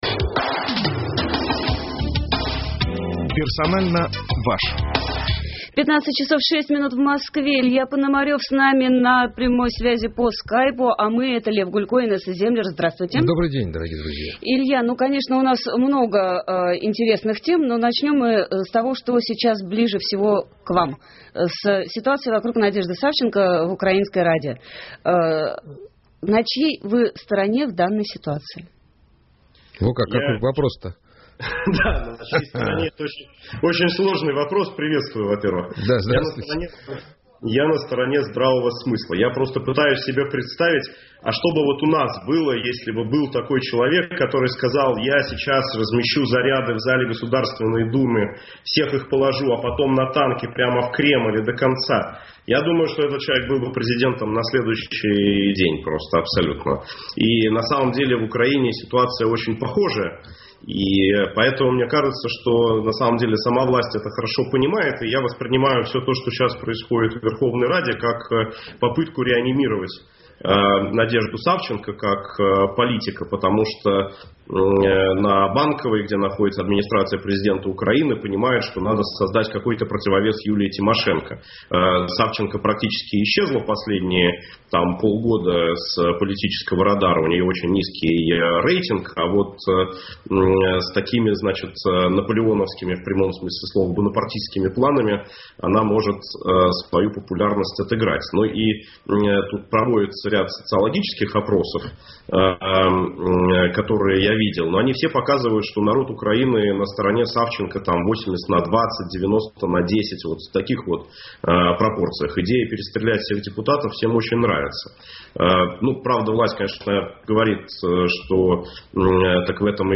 Илья Пономарев с нами на прямой связи по Скайпу.